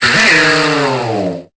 Cri d'Écaïd dans Pokémon Épée et Bouclier.